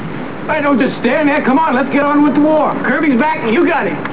Jack Hogan as Kirby
Dialog Excerpts